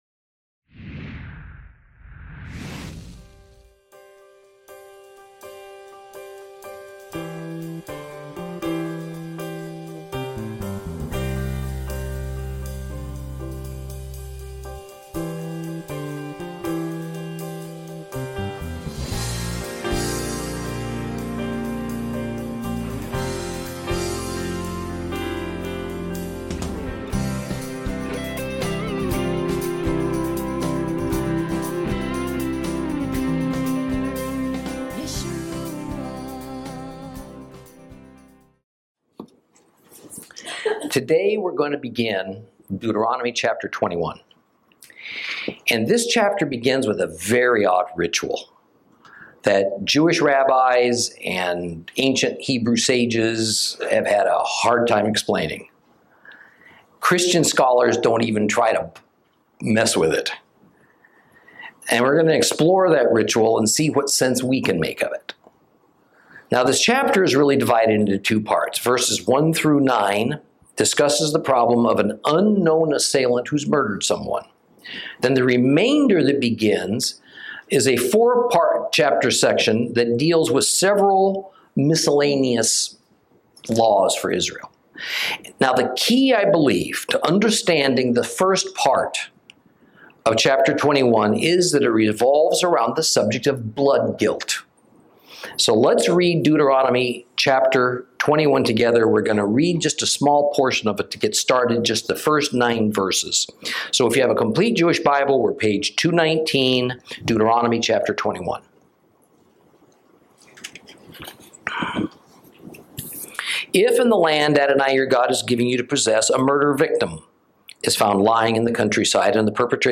Lesson 26 Ch21 - Torah Class